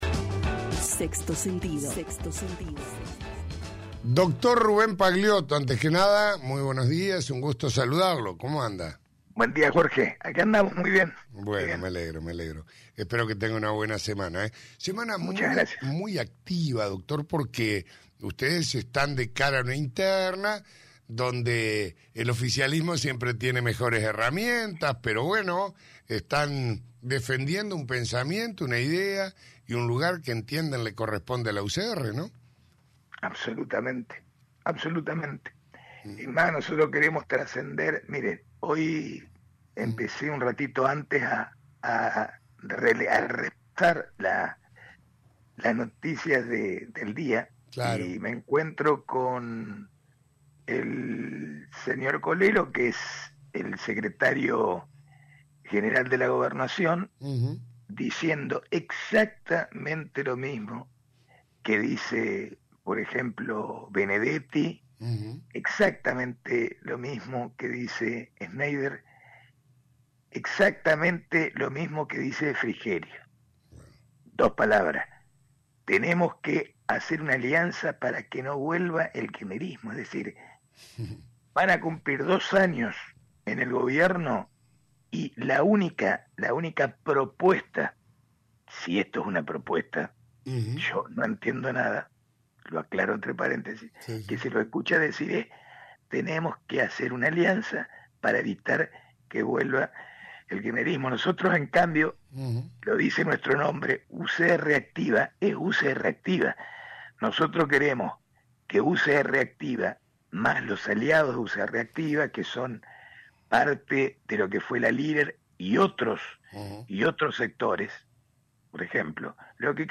Durante una entrevista